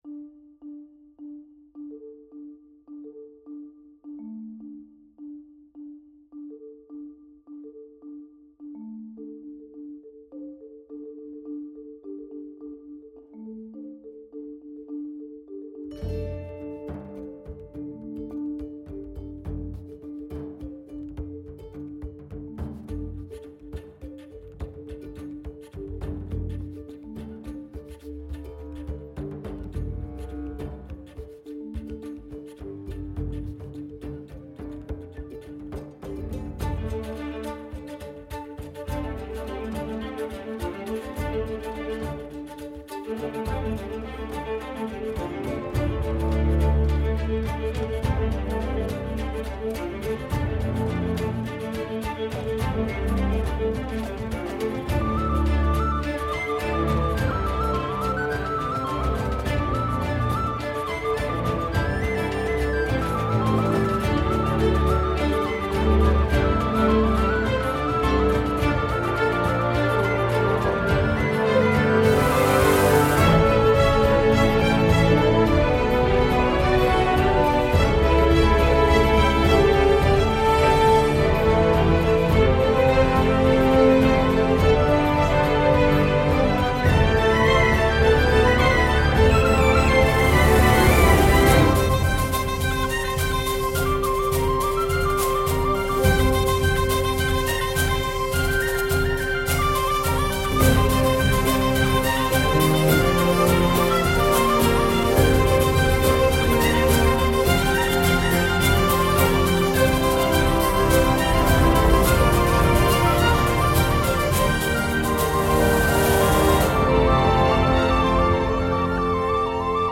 thème principal énergique aux couleurs celtiques